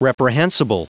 Prononciation du mot reprehensible en anglais (fichier audio)
Prononciation du mot : reprehensible